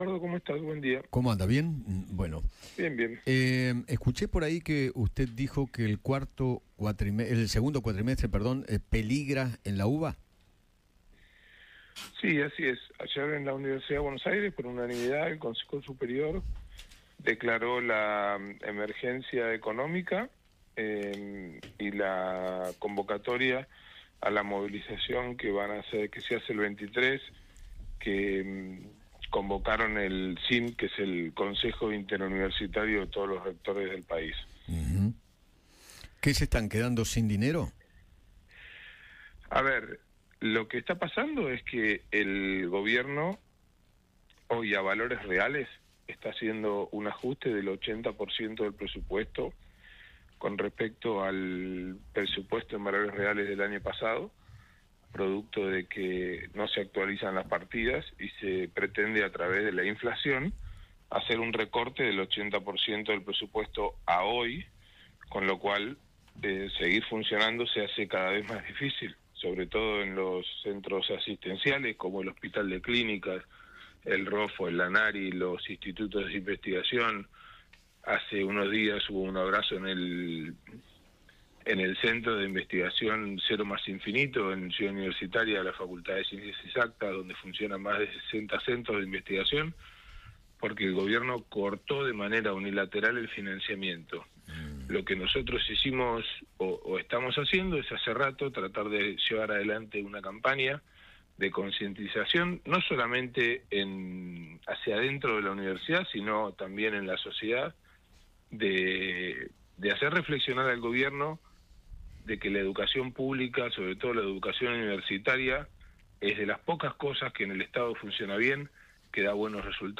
Emiliano Yacobitti, vicerrector de la UBA, dialogó con Eduardo Feinmann sobre el ajuste en el presupuesto de las Universidades que está sosteniendo el Gobierno.